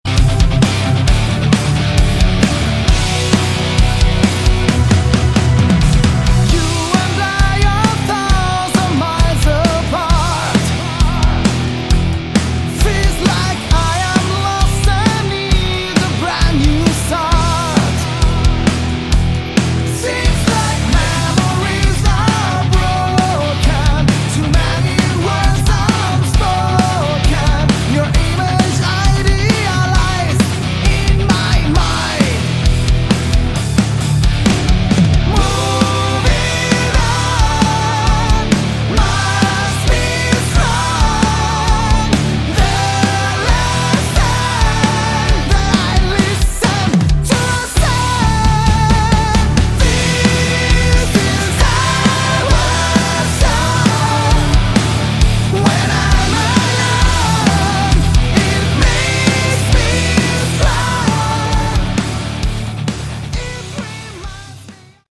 Category: Melodic Rock
vocals
guitar
bass
keyboard
drums